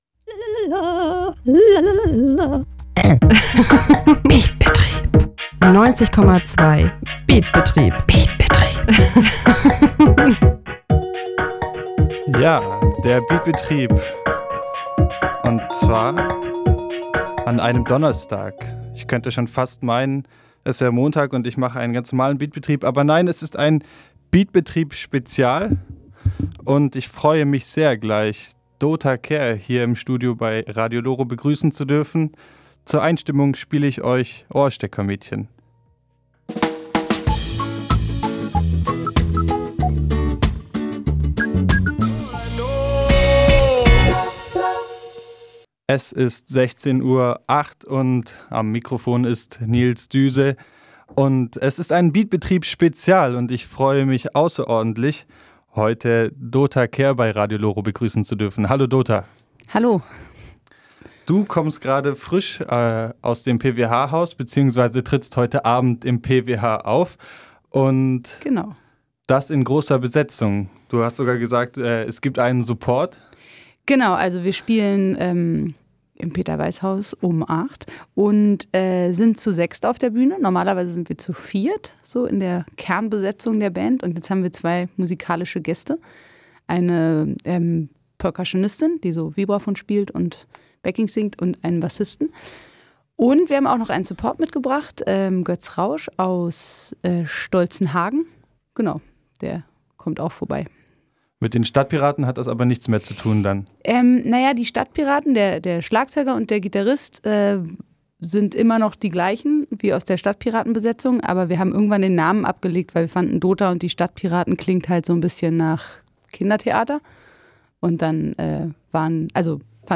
Interview-Dota-ohne-Musik-fertig.mp3